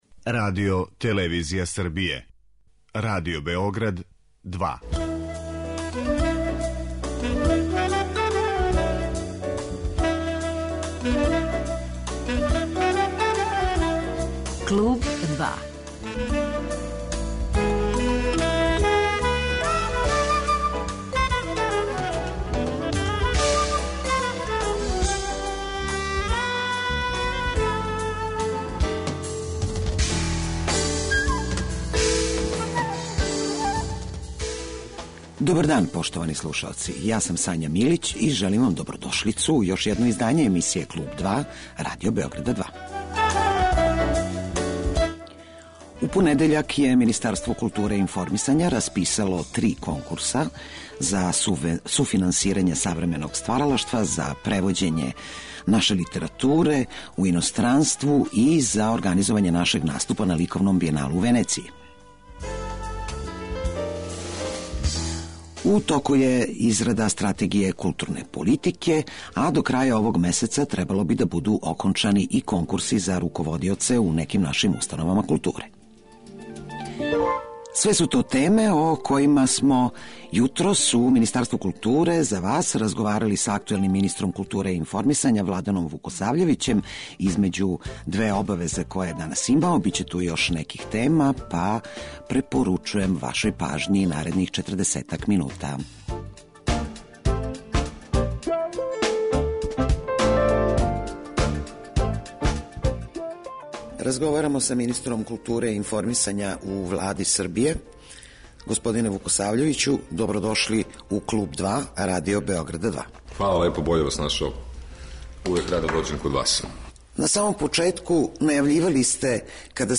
Гост емисије 'Клуб 2' биће Владан Вукосављевић, министар културе и информисања у Влади Републике Србије.